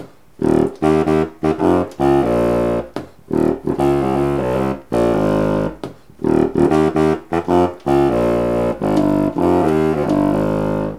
WAV 948K, mp3) and a RealAudio file of me playing a (narrow bore) Evette & Schaeffer EEb contra.
It strikes me as much quieter than the Gautrot, but that may just be the reeds I've been using (bass sordune for the E&S contra, great bass sordune for the Gautrot, both obtained from the Early Music Shop).